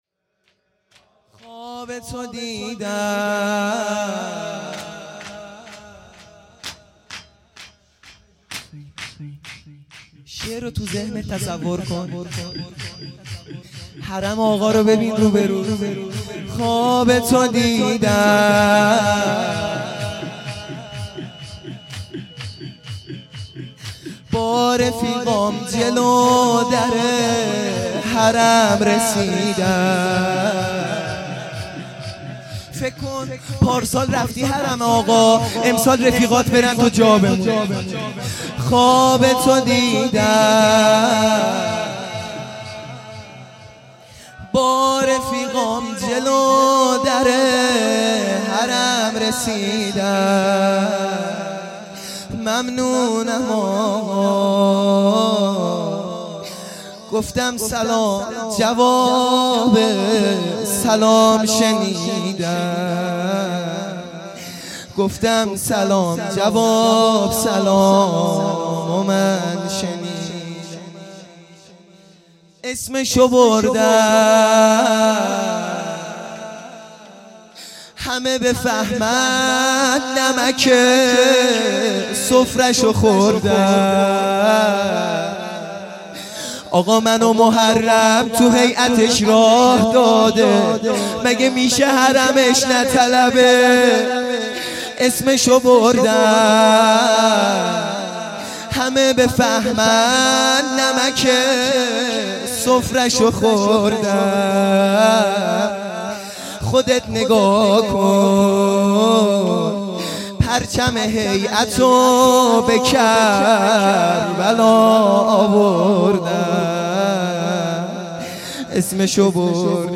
شور
دهه اول صفر | شب اول